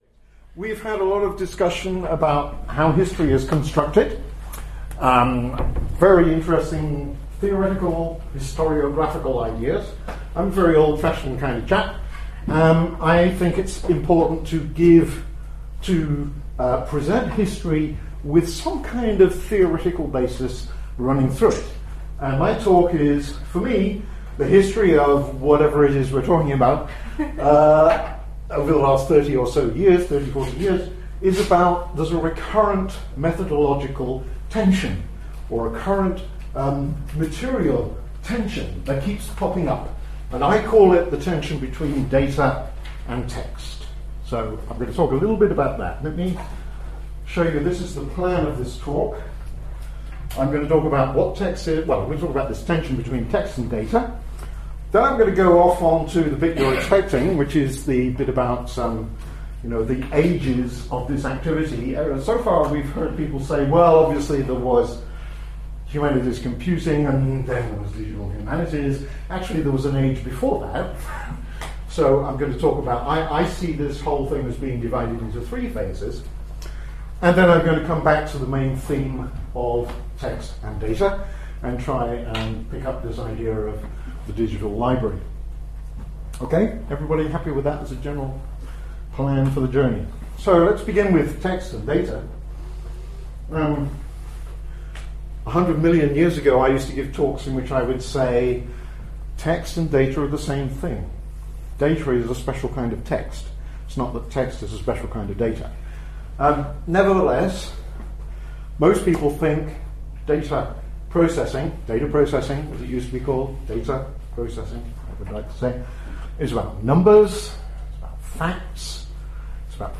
Presentation
at the Hidden Histories Symposium, September 2011, UCL.